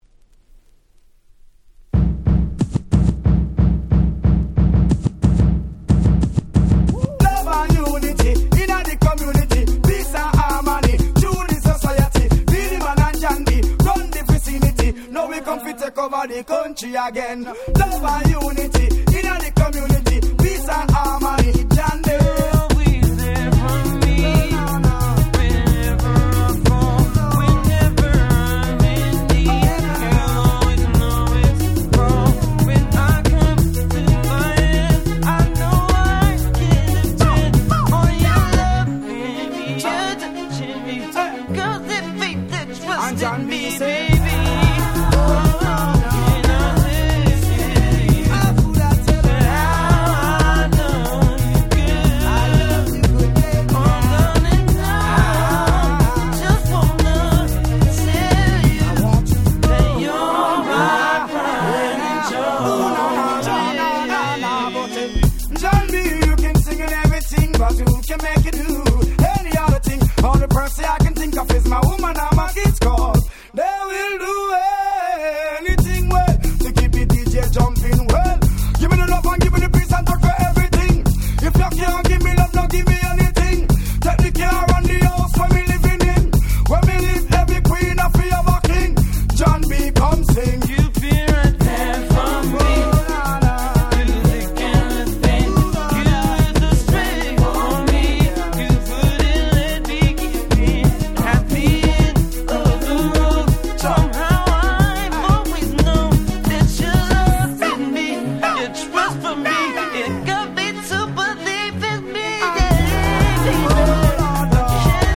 98' Dancehall Reggae Super Classics !!